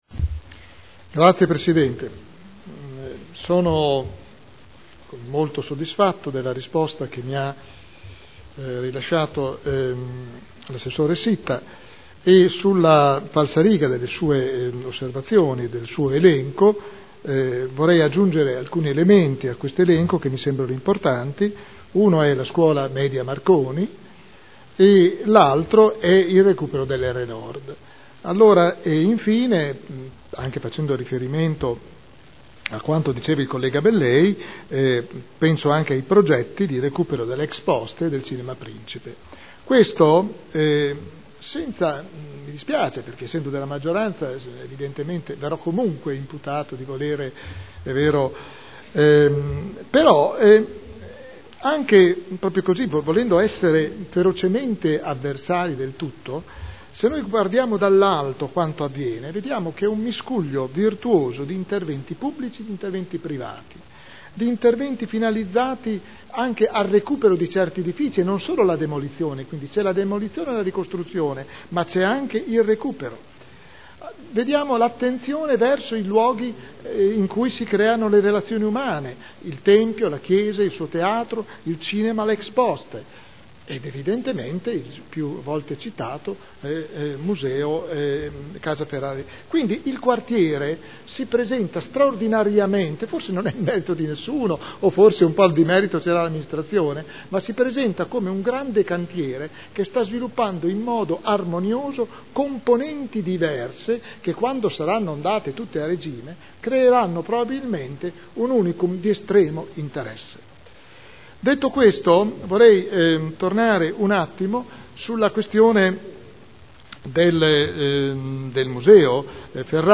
William Garagnani — Sito Audio Consiglio Comunale